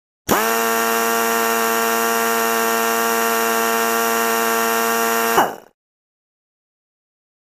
Pneumatic Drill
Small Pneumatic Drill; On, Steady, Off; Pneumatic Drill; On / Steady / Off, Isolated, Close Perspective.